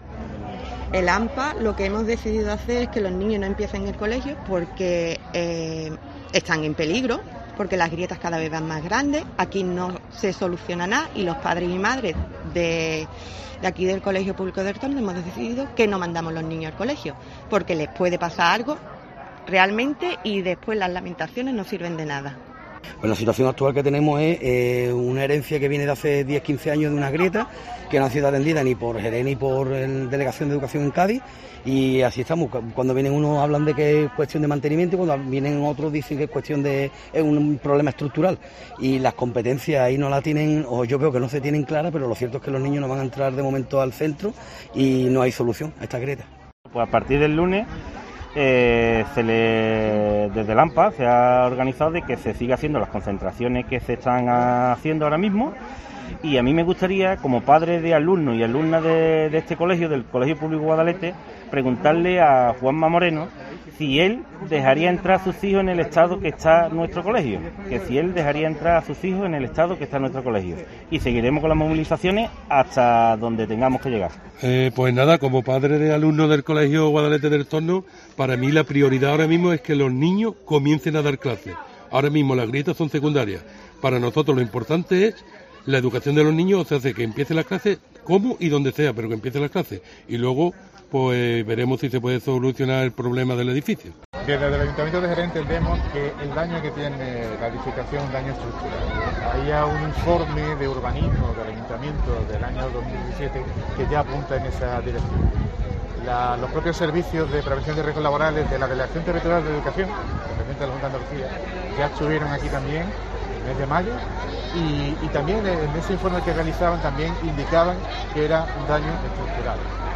Escucha a los representantes de la comunidad educativa del CEIP 'Guadalete' de El Torno y a los representantes del Ayuntamiento matriz de Jerez y del pedáneo sobre la situación del centro educativo